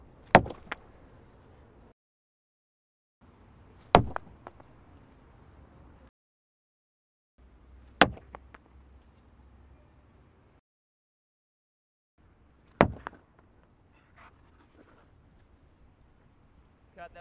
09 冰上曲棍球的效果流动
描述：在海牙的Uithof录制的比赛，冰球比赛
标签： 匹配 曲棍球 哨子 团队 冰球 滑冰 比赛 冰球 滑冰
声道立体声